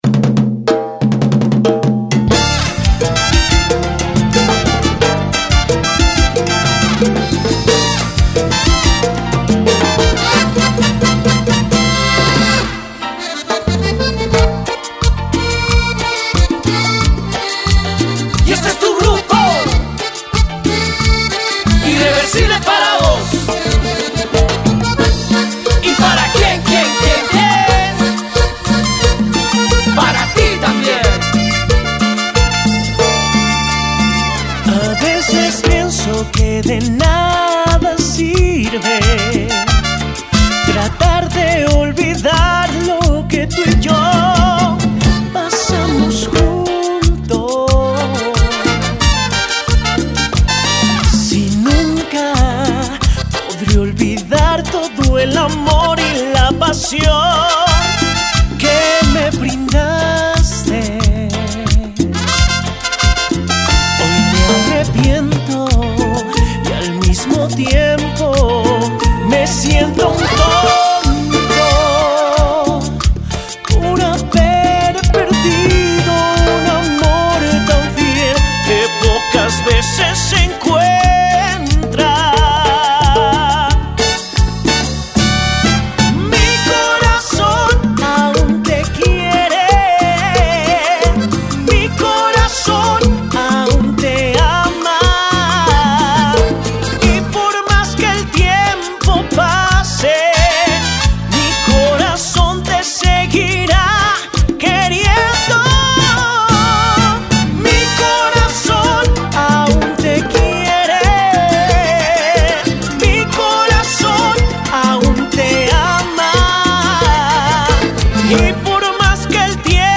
Cumbia Latina